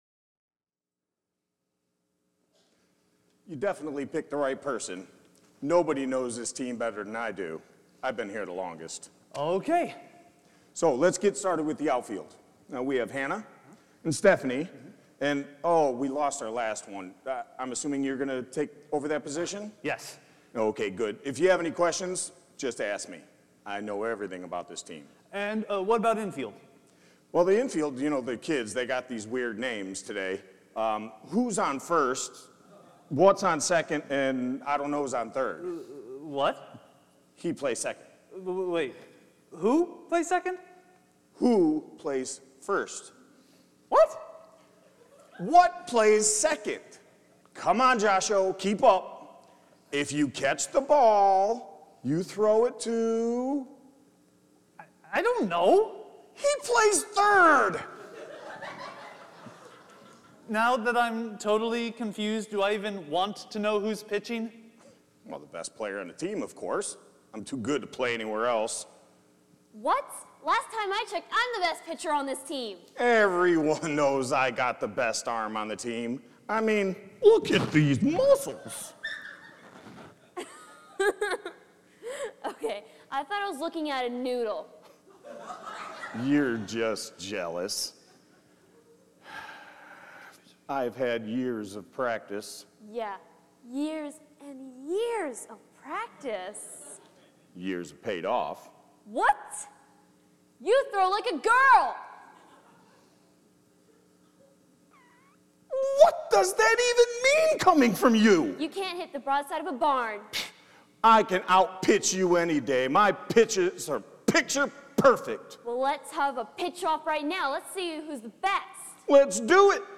Individual Messages Service Type: Sunday Morning Jesus looked at His disciples and said